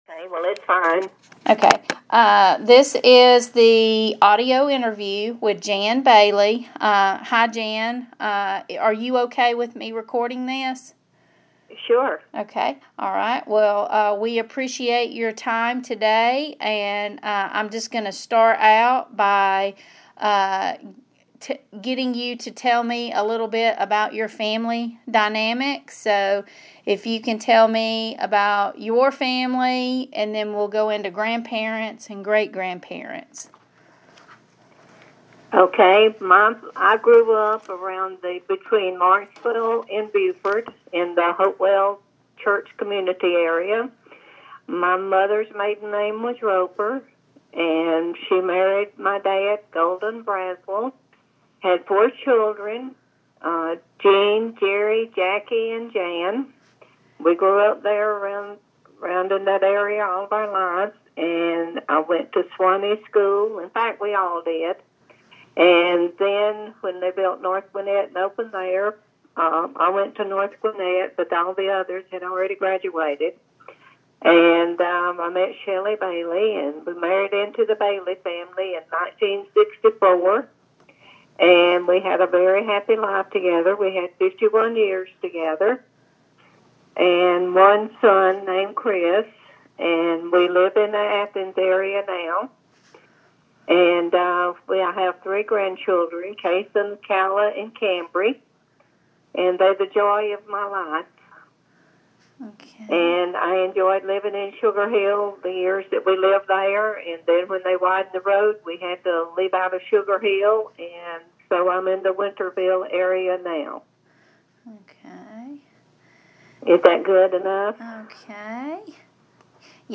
Oral histories
via telephone